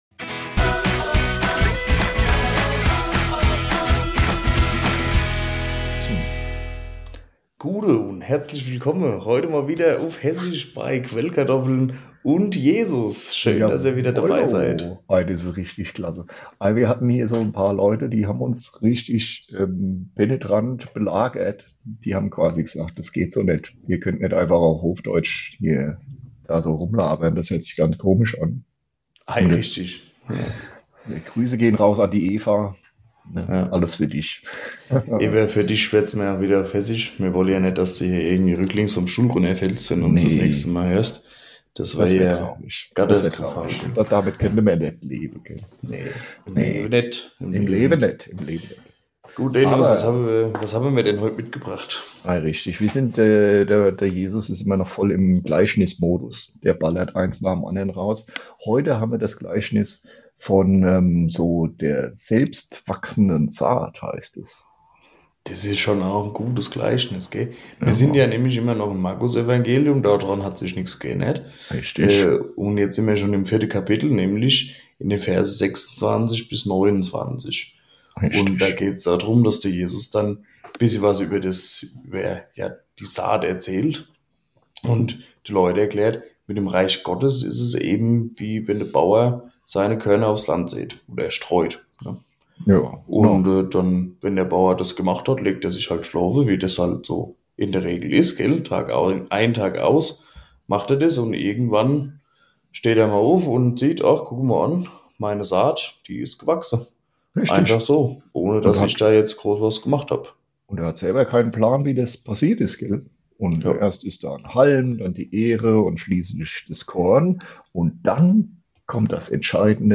Ein Rückfall in alte hessische Zeiten erwartet euch und vor allem